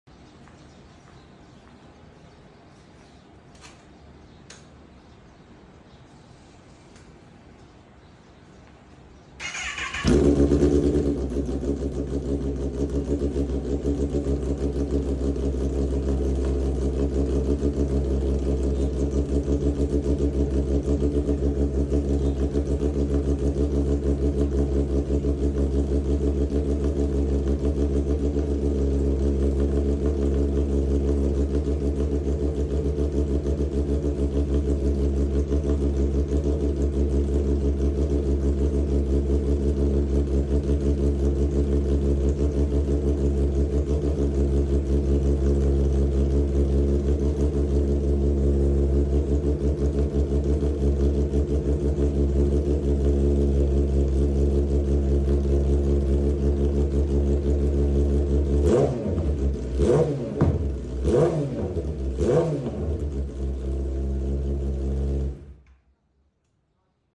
Kawasaki Heavy Bike Sound with sound effects free download
Kawasaki Heavy Bike Sound with Flames!